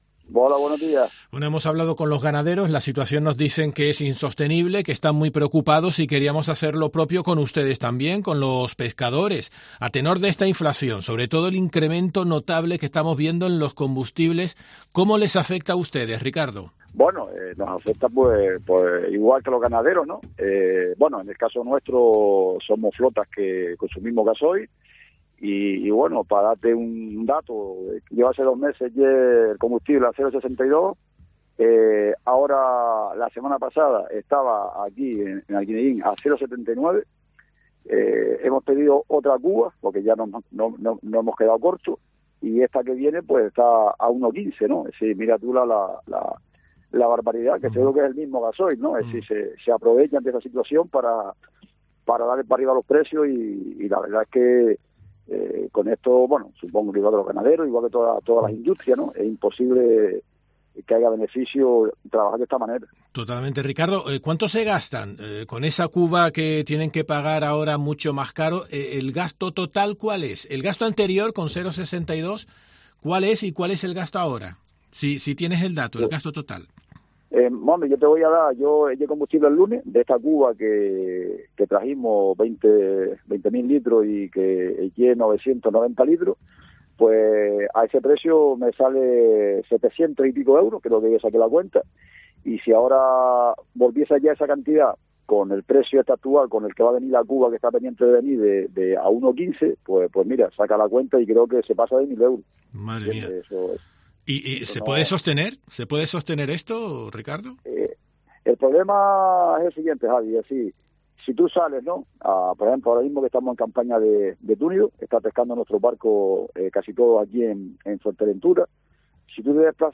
En los micrófonos de COPE Gran Canaria han denunciado la situación que están viviendo y aseguran que “la subida de precios nos afecta lo mismo que a los ganaderos, porque consumimos mucho gasoil”.